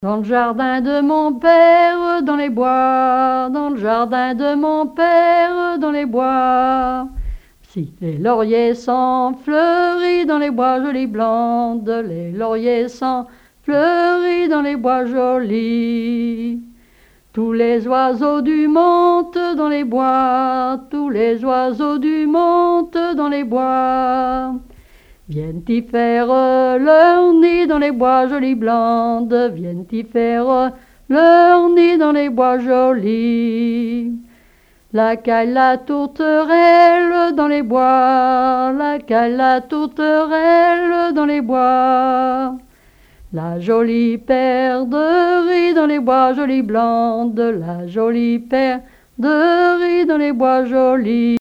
Témoignages et chansons traditionnelles
Pièce musicale inédite